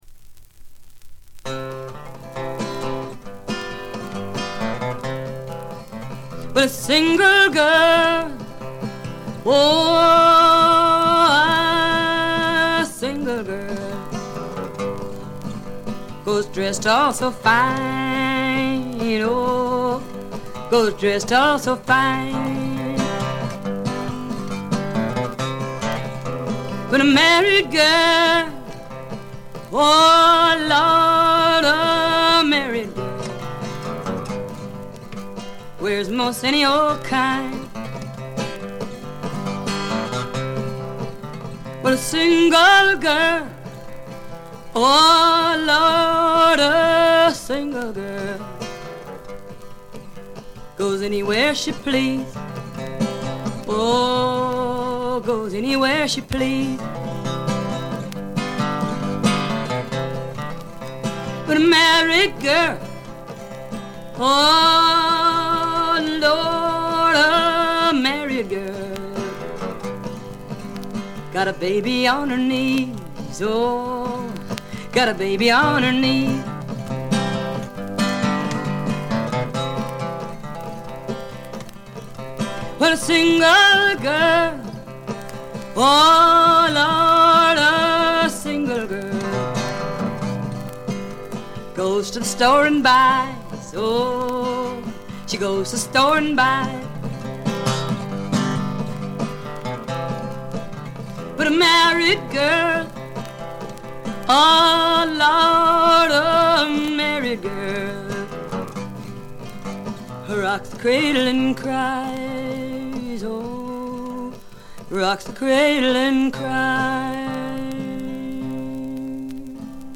バックグラウンドノイズ、チリプチは常時大きめに出ます。
存在感抜群のアルト・ヴォイスが彼女の最大の武器でしょう。
試聴曲は現品からの取り込み音源です。